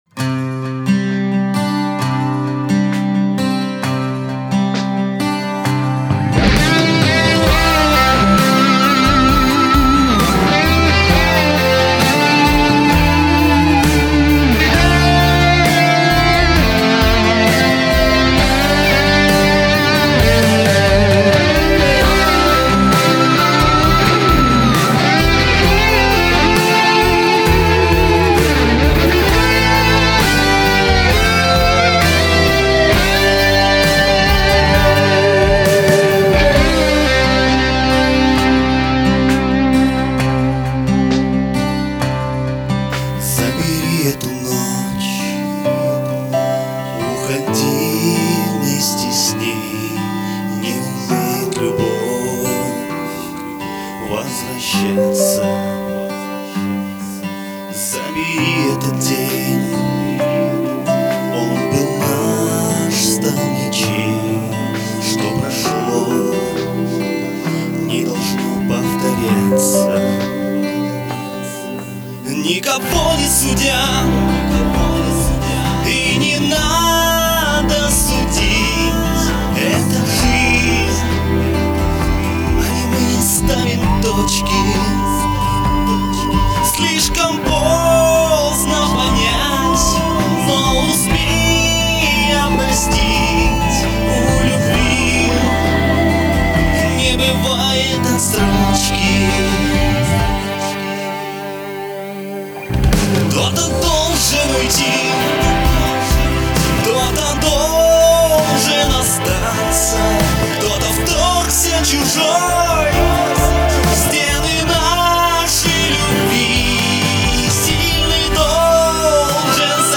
Разные манеры одного исполнителя.
Оба Молодца, голоса и спето красиво!